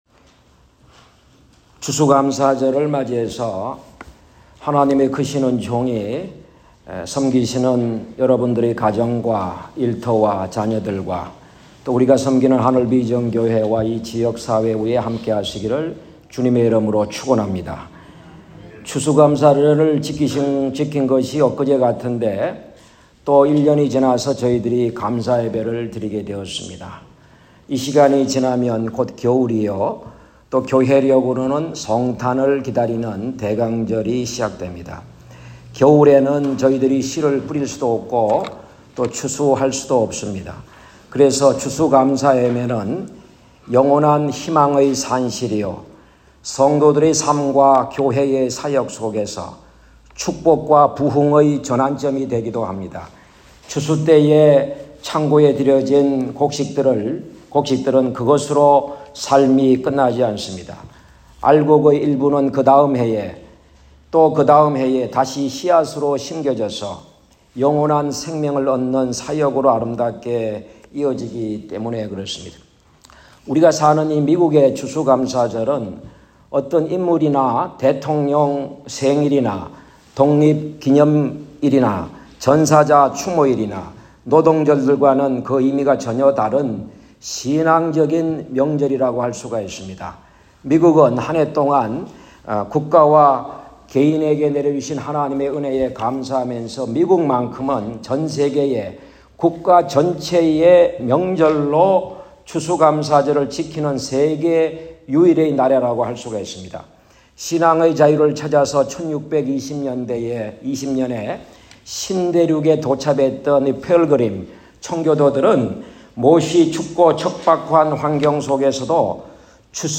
2024년 11월 24일 추수감사주일 (오전 11시)
2024년-추수감사절-설교.m4a